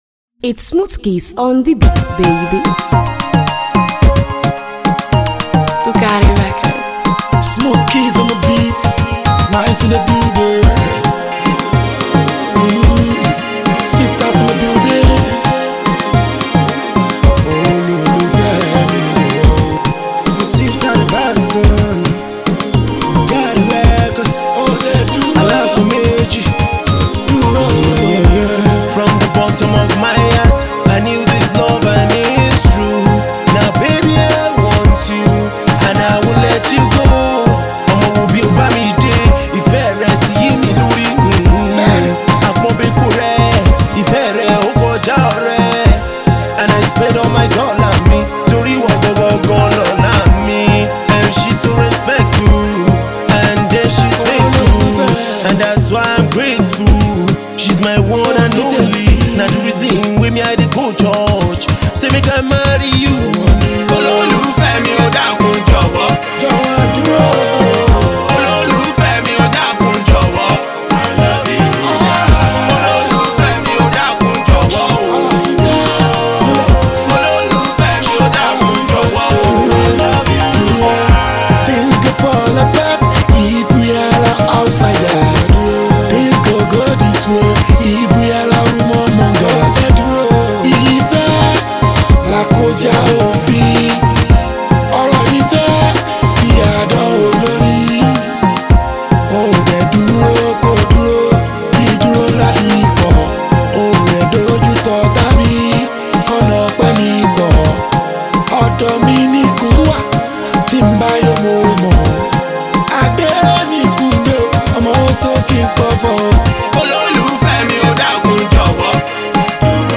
Pop
love song